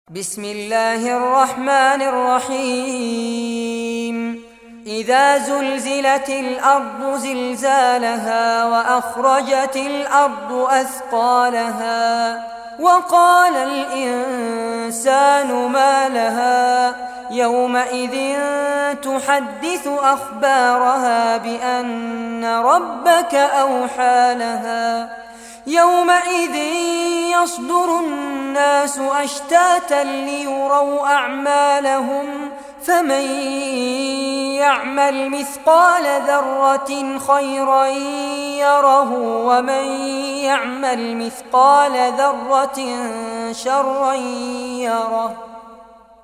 Surah Az-Zalzalah Recitation by Fares Abbad
Surah Az-Zalzalah, listen or play online mp3 tilawat / recitation in Arabic in the beautiful voice of Sheikh Fares Abbad.